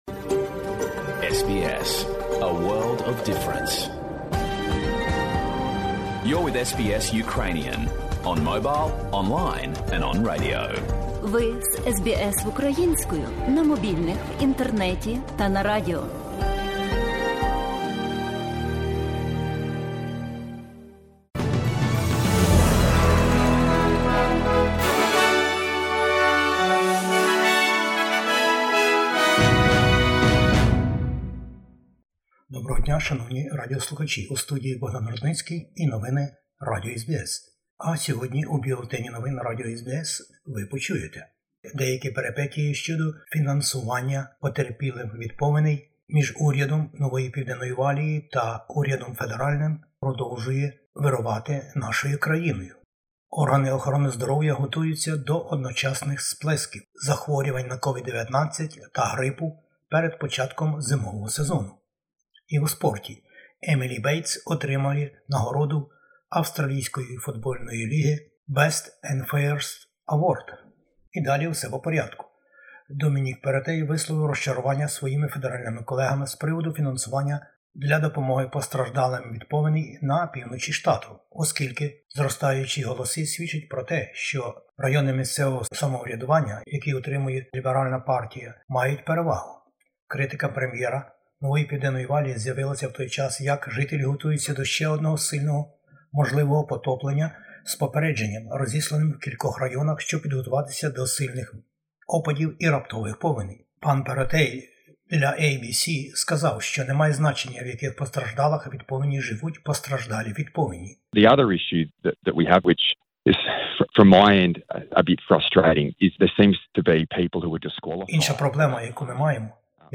Бюлетень новин SBS українською. Передвиборчі перипетії набирають руху активнішого напередодні федеральних виборів в Австралії.